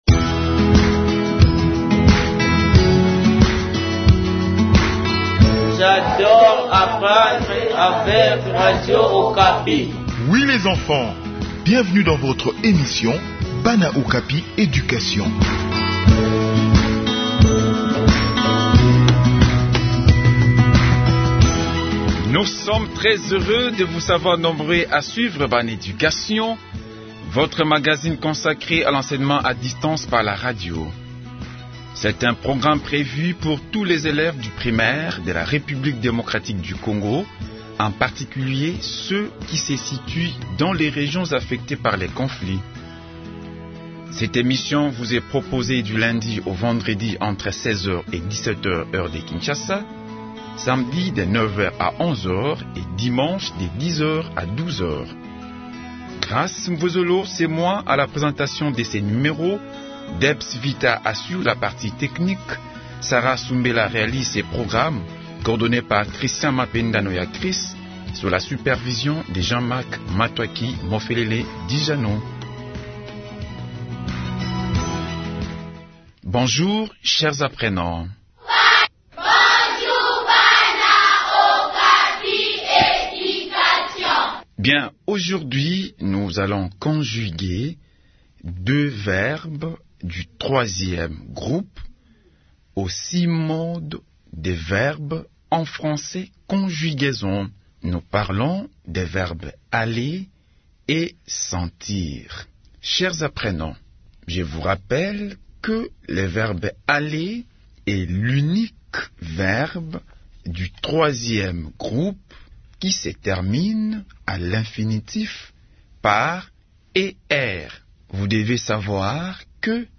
Enseignement à distance : leçon de conjugaison des verbes Aller et sentir